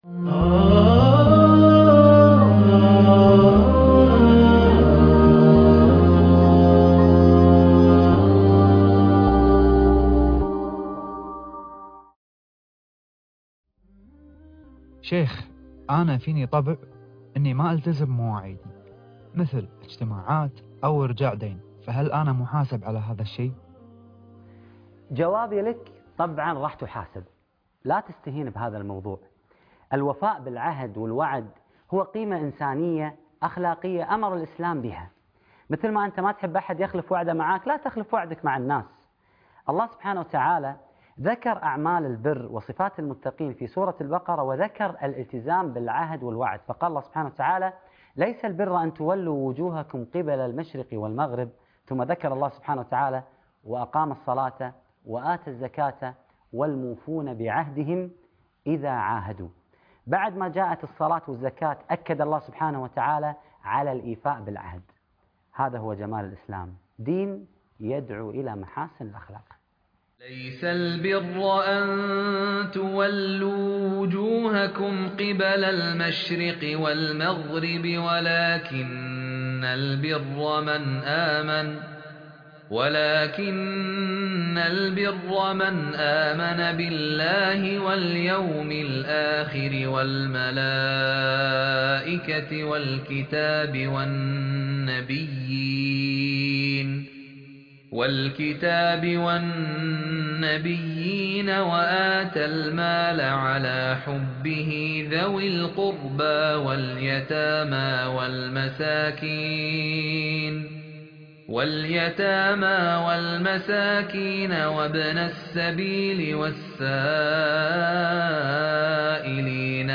علمني القرآن الحلقة الرابعة و العشرون _ ضياع العهد والوعد - القاريء فهد الكندري